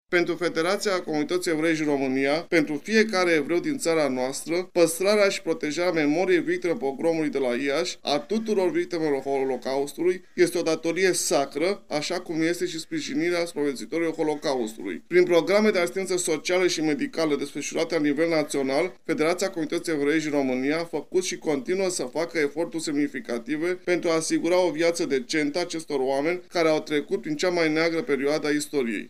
Astăzi la Iaşi sunt comemorate victimele Pogromului din 1941.